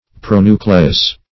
Pronucleus \Pro*nu"cle*us\, n.; pl. Pronuclei (-[imac]). [NL.
pronucleus.mp3